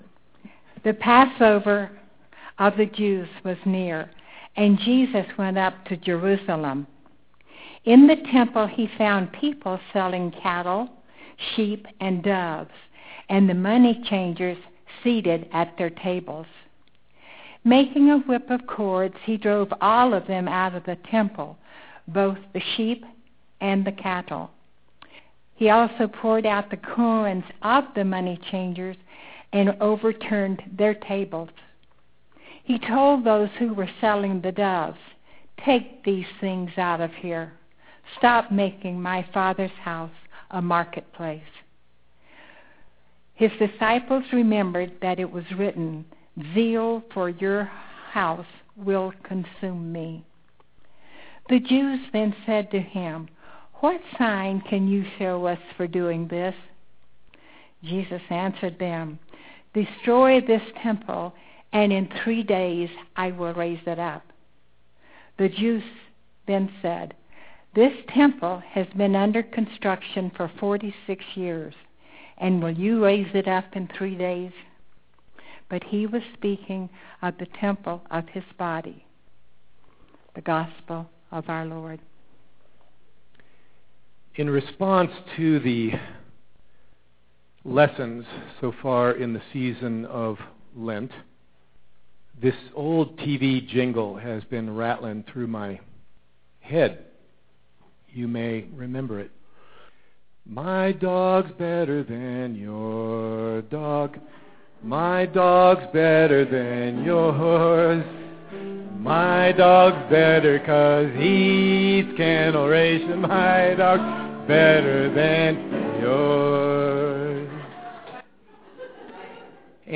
(Sorry for the buzzing, we have audio level problems this week.)